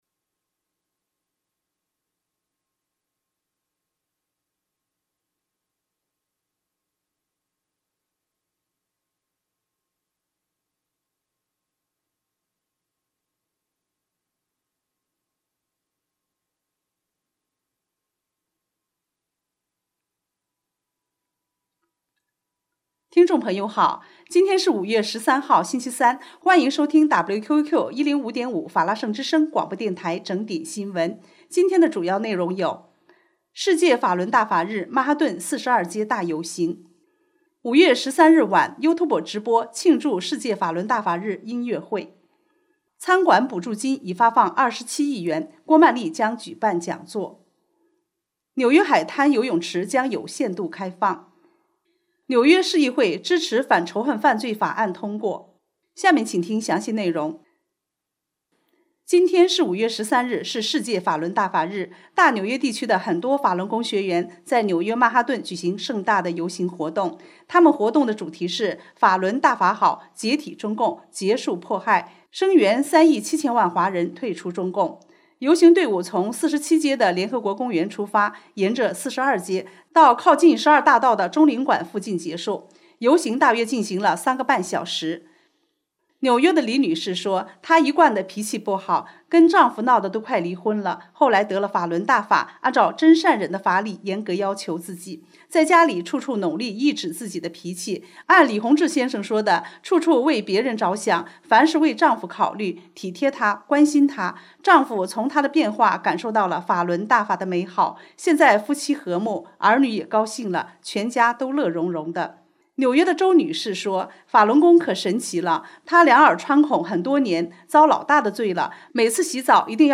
5月13日（星期四）纽约整点新闻